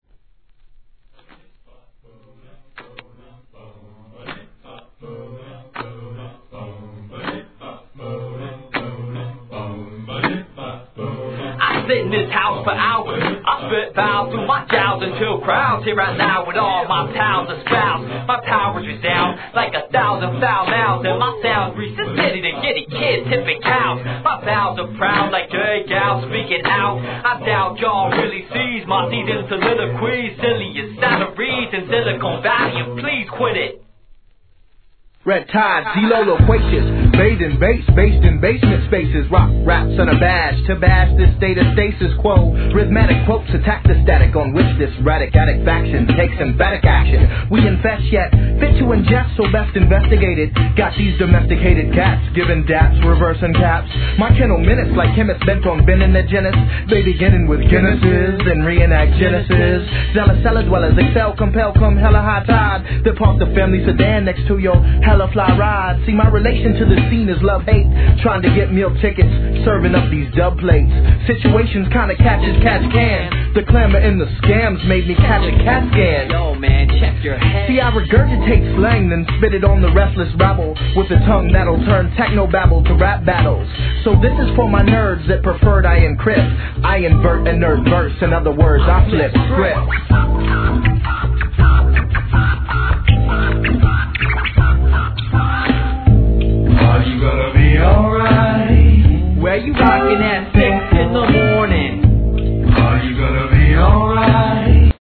HIP HOP/R&B
L.A.アンダーグランド! かなりのユルノリ具合は好きな人にはたまらないでしょう!